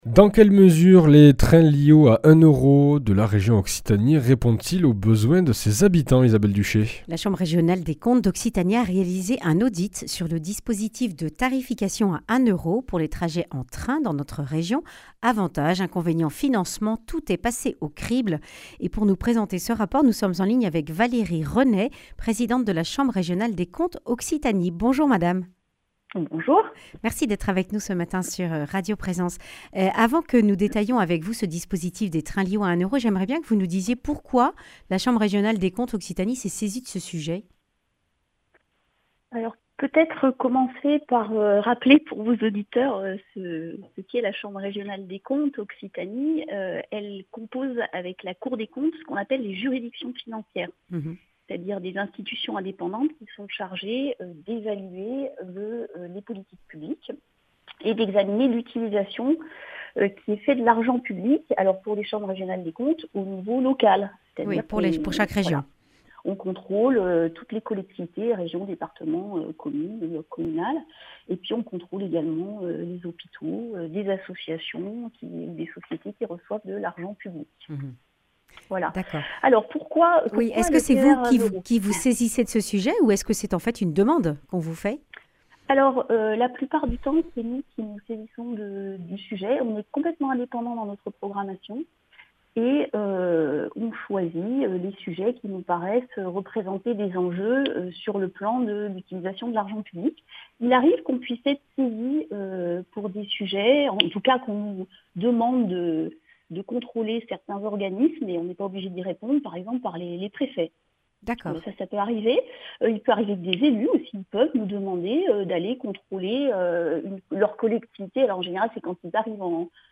Accueil \ Emissions \ Information \ Régionale \ Le grand entretien \ Le train à 1 euro change t-il les habitudes de transport des usagers ?